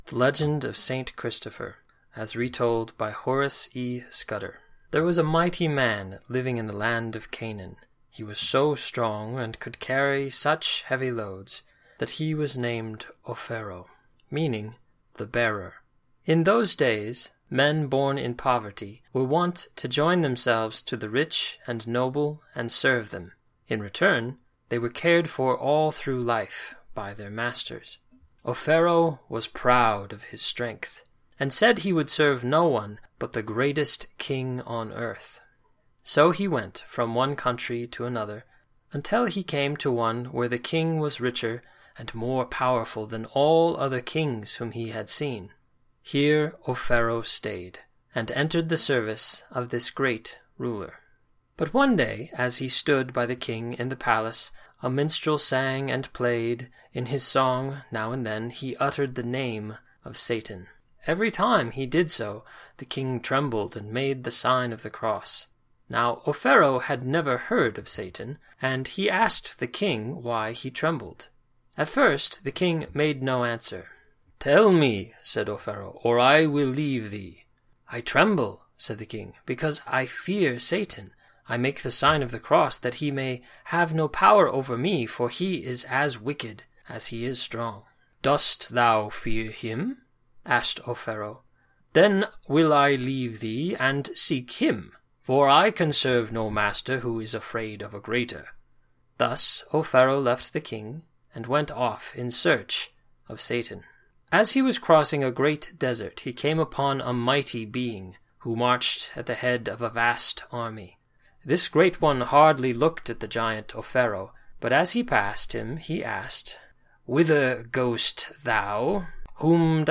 Family Story Hour, Advent & Christmas combines poetry, essay, and storytelling to give your family over an hour’s worth of listening pleasure. Featured authors include St. John Newman, O’Henry, and George MacDonald.